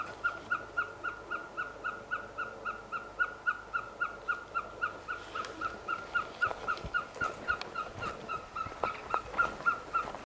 Caburé Grande (Glaucidium nana)
Nombre en inglés: Austral Pygmy Owl
Fase de la vida: Adulto
Localidad o área protegida: Lago Rivadavia- P.N. Los Alerces
Condición: Silvestre
Certeza: Observada, Vocalización Grabada